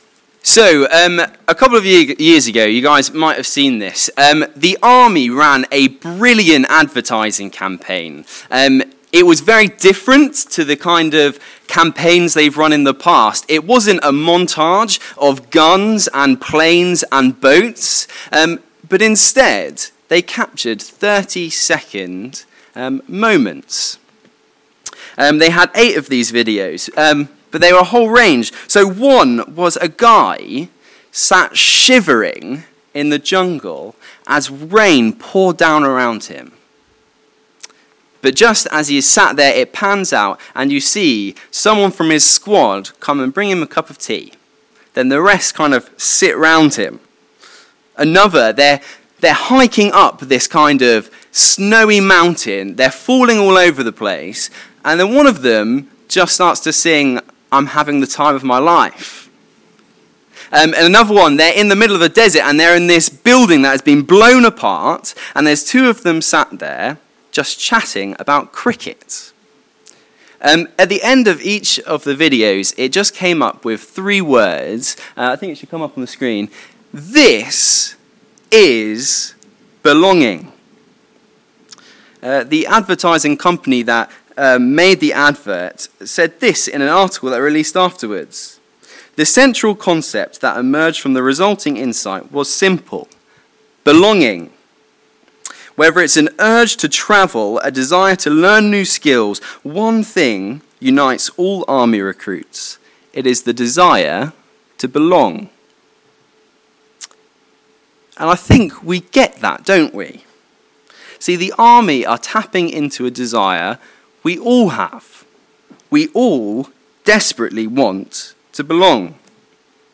Sermon Video